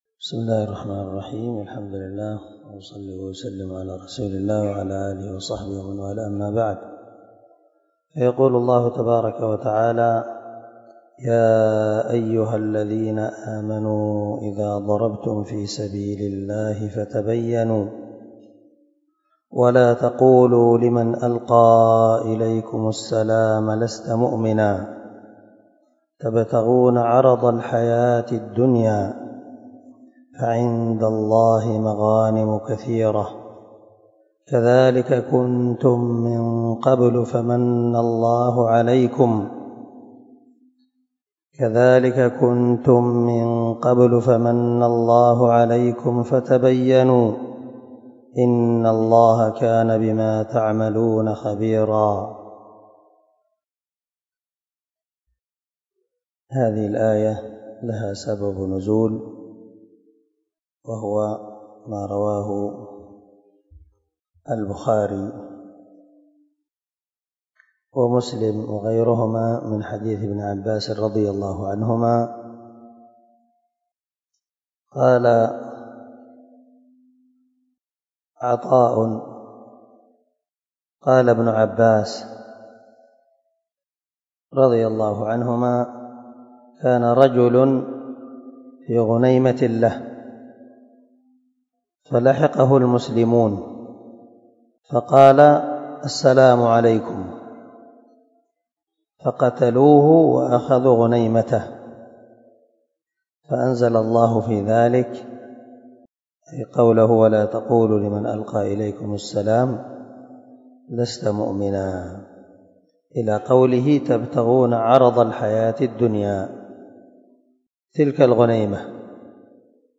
295الدرس 63 تفسير آية ( 94 ) من سورة النساء من تفسير القران الكريم مع قراءة لتفسير السعدي
دار الحديث- المَحاوِلة- الصبيحة.